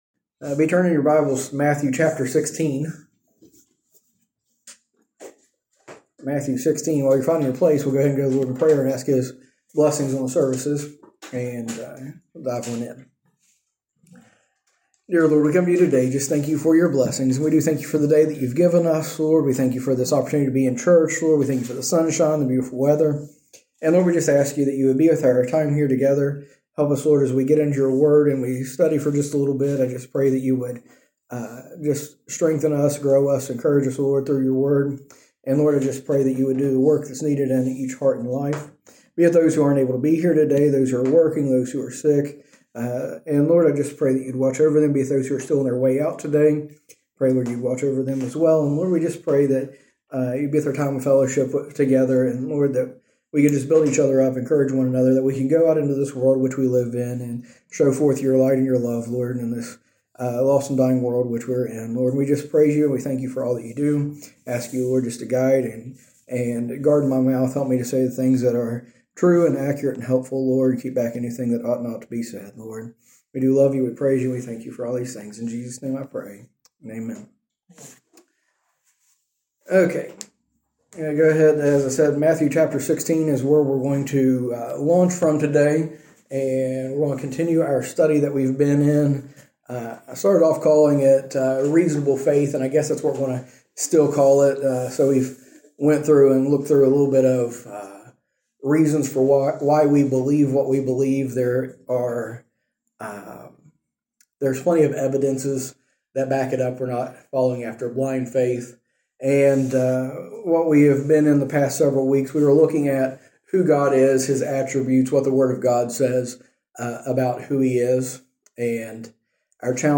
A message from the series "A Reasonable Faith."